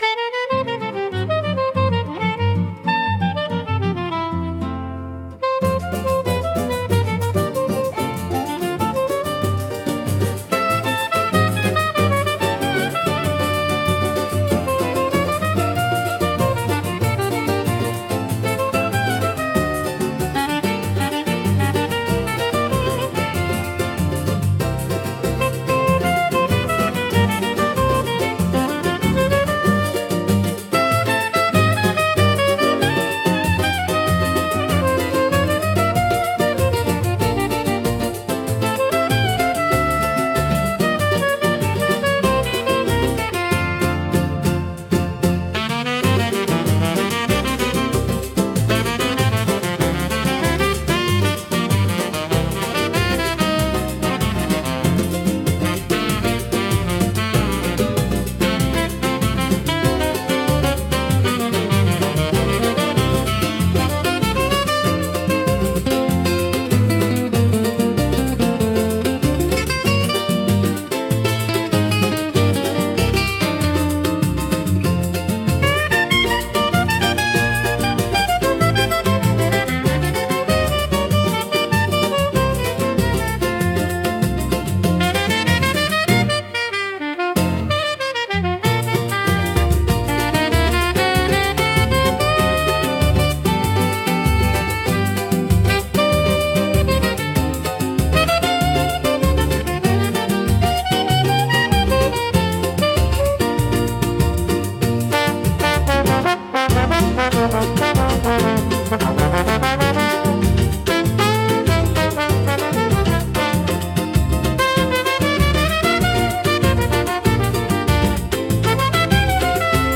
música e arranjo: IA) instrumental 5